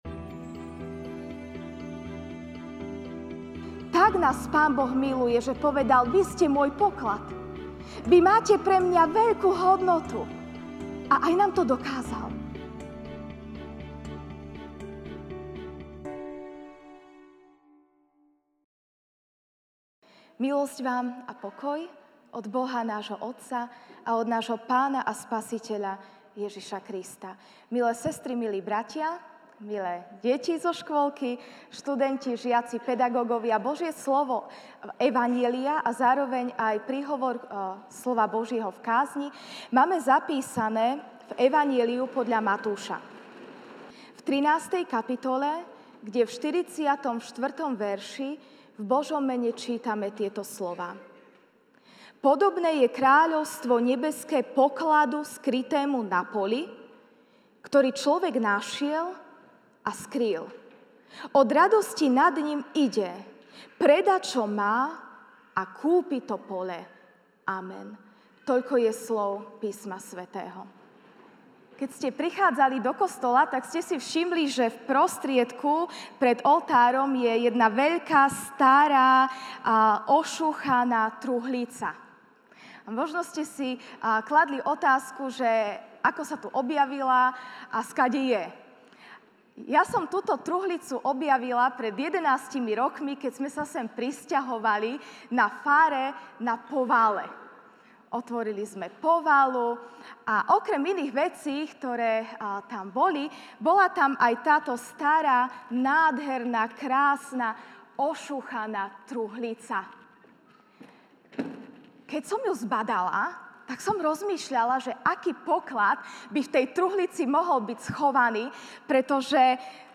jún 23, 2019 Poklad MP3 SUBSCRIBE on iTunes(Podcast) Notes Sermons in this Series Ranná kázeň: Poklad (Mt 13, 44) Podobné je kráľovstvo nebeské pokladu skrytému na poli, ktorý človek našiel a skryl; od radosti nad ním ide, predá, čo má, a kúpi to pole.